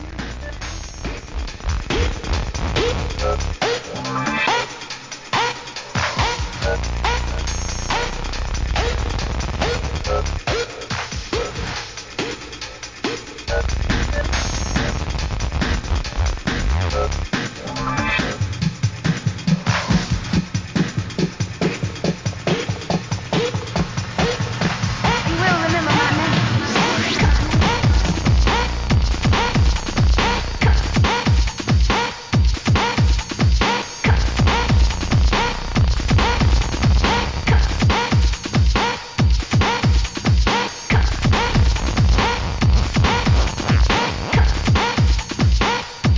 UKブレイクビーツ